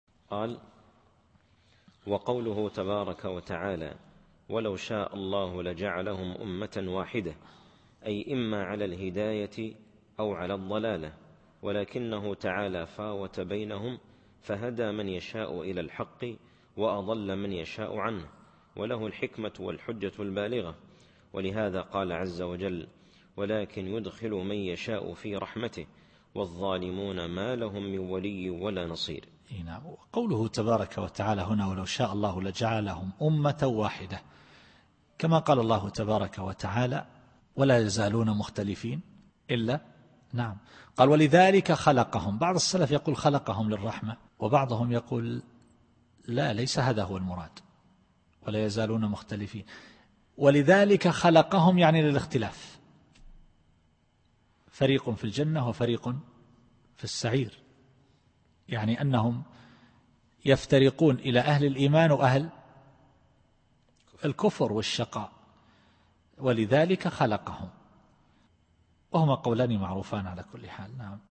التفسير الصوتي [الشورى / 8]